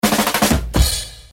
DRUM